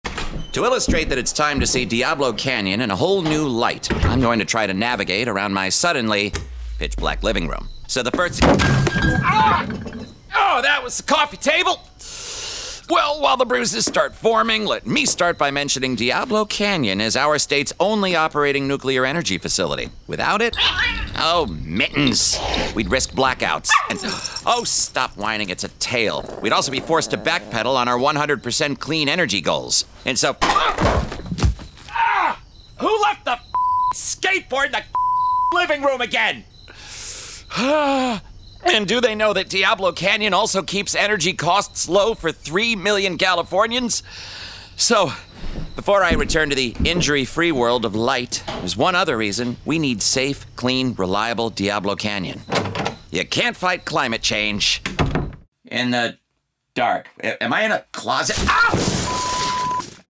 It’s comedy.
It’s got high production values.